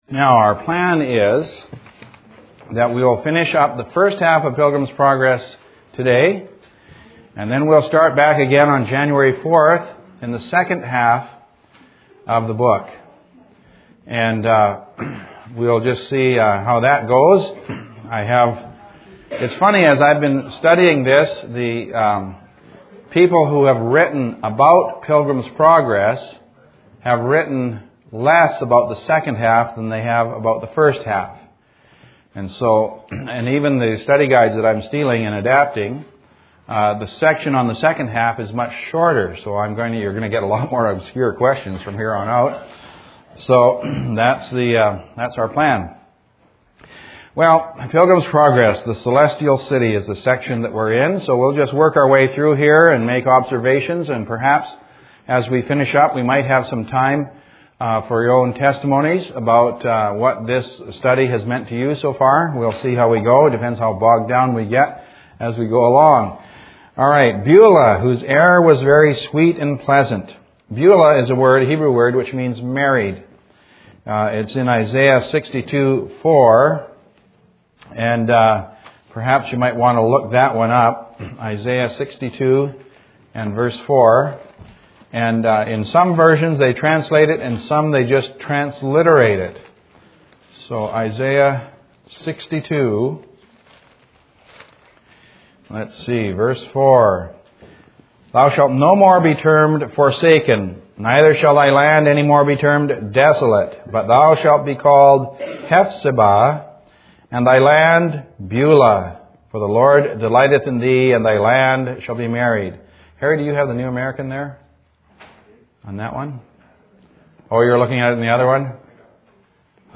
Our folks gave what I thought were moving testimonies at the end of this session on that subject. I amplified their words so that you can hear them also, hopefully the distortions at that amplification will not be too distracting.